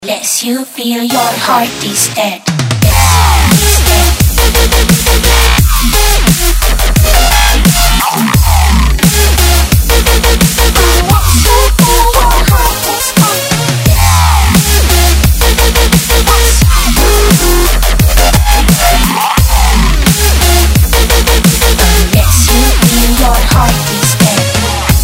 Категория: Dubstep рингтоны